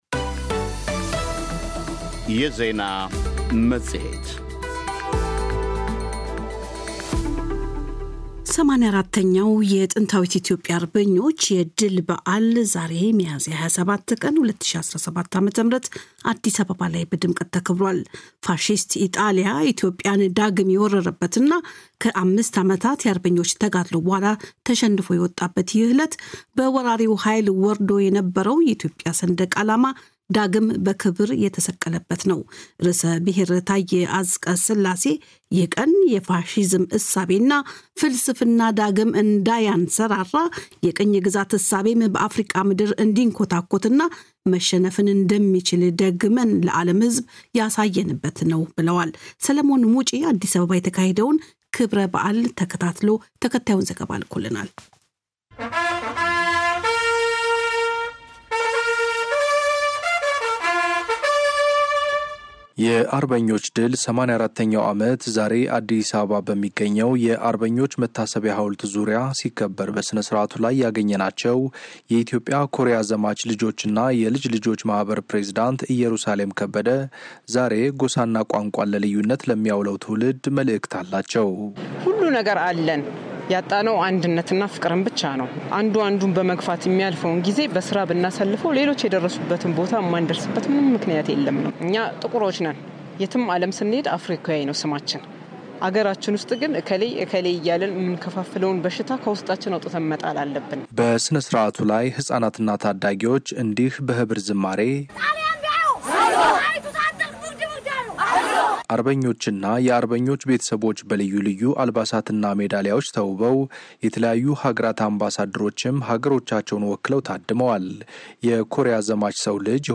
በዐበይት የኢትዮጵያ፣ የአፍሪካ፣ የአውሮፓ እና የዓለም ጉዳዮች ላይ ዜናዎች፣ ቃለ-መጠይቆች እና ጥልቅ ትንታኔዎች ይቀርባሉ።
ኢትዮጵያን ጨምሮ በተለያዩ የዓለም ክፍሎች የሚገኙ የዶይቼ ቬለ ዘጋቢዎች የሚያጠናቅሩት ዜና መፅሔት ከሰኞ እስከ አርብ ከዓለም ዜና ቀጥሎ ይቀርባል።